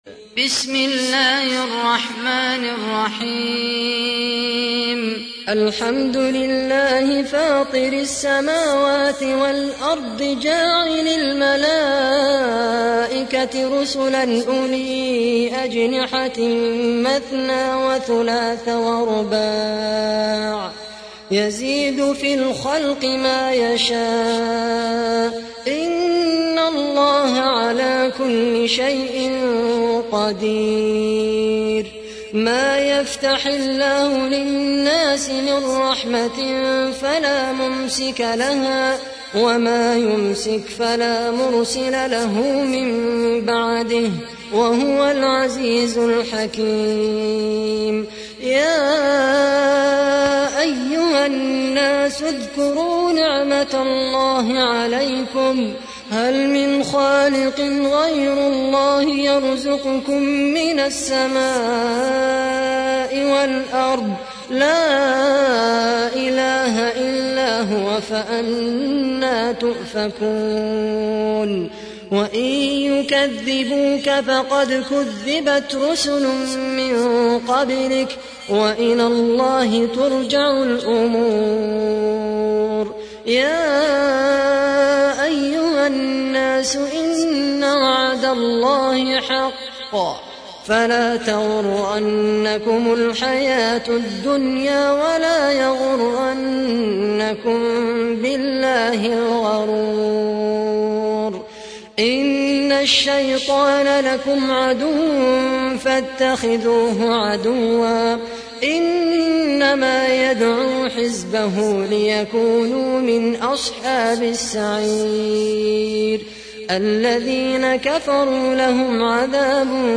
تحميل : 35. سورة فاطر / القارئ خالد القحطاني / القرآن الكريم / موقع يا حسين